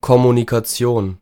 Ääntäminen
Synonyymit Informationsaustausch Ääntäminen : IPA: [kɔmunikaˈtsi̯oːn] High German: IPA: /komunikaˈtsjoːn/ Haettu sana löytyi näillä lähdekielillä: saksa Käännös Ääninäyte Substantiivit 1. communication US Artikkeli: die . Taivutusmuodot Monikko Kommunikationen Luokat Substantiivit